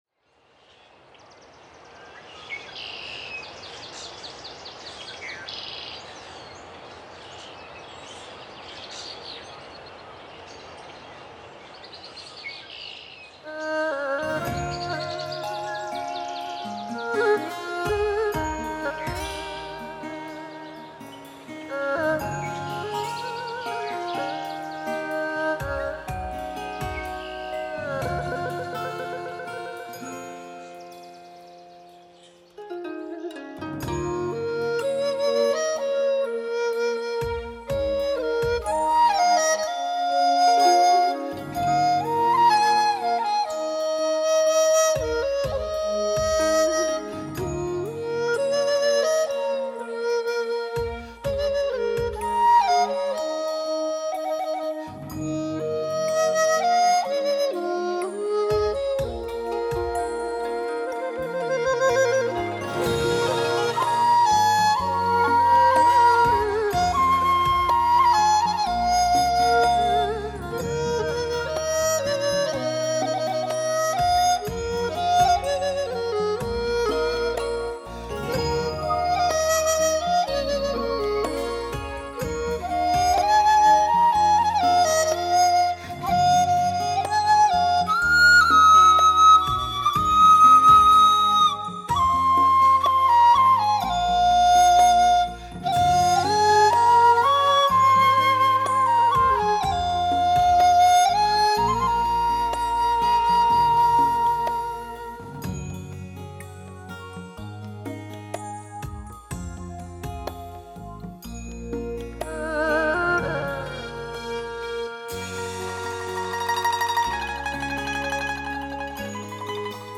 音乐类型：民乐
DTS6.1环绕声特赏版,多声道音乐典范录音。
完美环绕+最新科技DTS-ES6.1CD 最逼真还原典范民乐演奏现场。